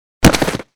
倒地-YS070510.wav
通用动作/01人物/02普通动作类/倒地-YS070510.wav
• 声道 單聲道 (1ch)